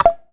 pgs/Assets/Audio/Custom/UI/Fail.wav at master
Fail.wav